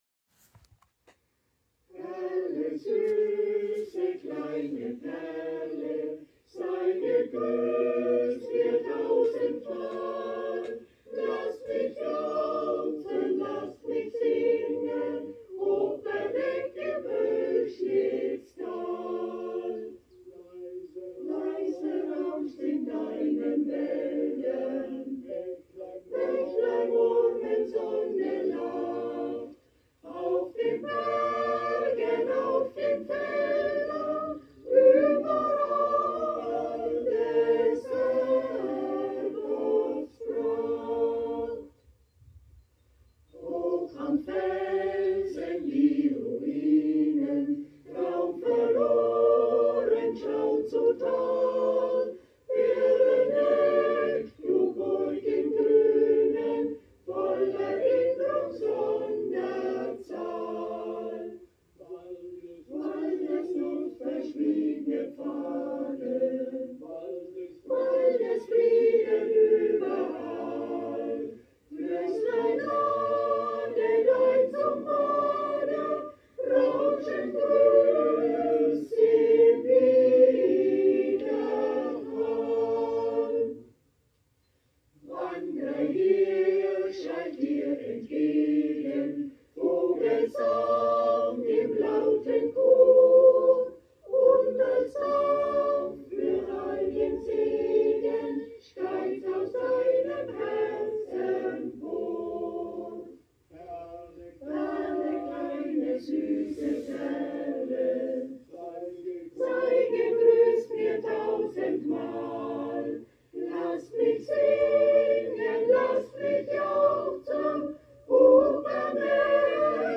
„Perle, süße kleine Perle“ zum Nachhören Arrangiert und vorgetragen vom Gesangsverein Bad Berneck 1835 e.V., aufgenommen für die QR-Tour Bad Berneck & Goldkronach* , 2014.